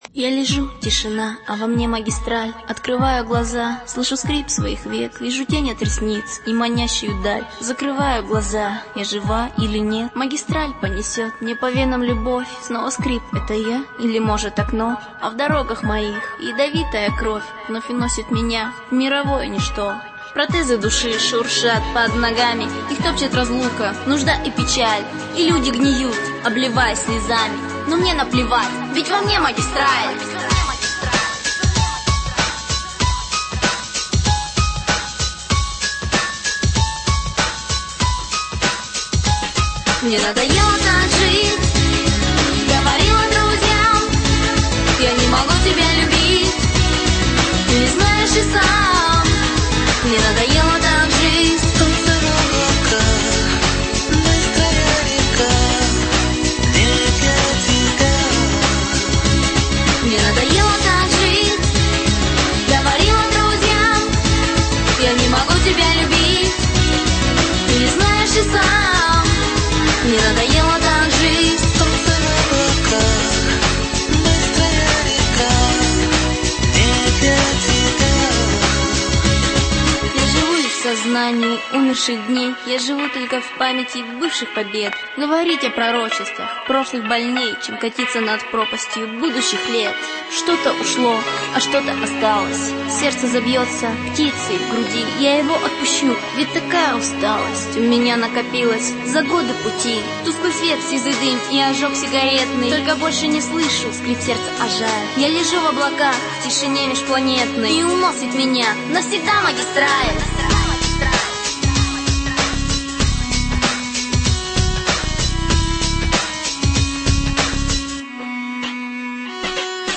sad sad sad такая грустная